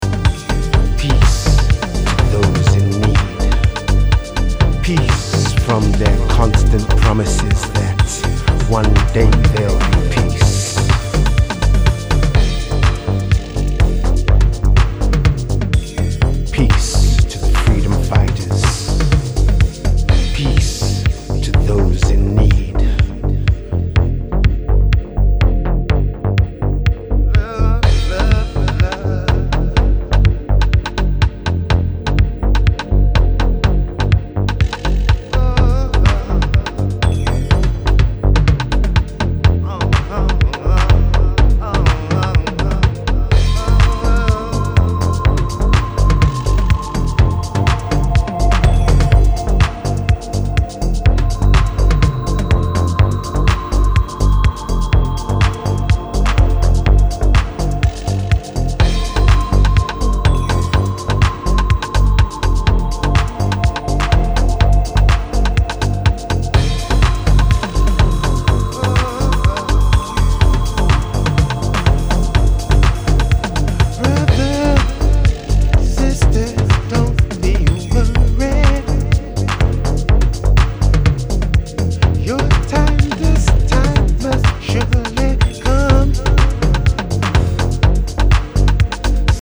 Classic Chicago house music.
mature classic early Chicago sounds and moody strings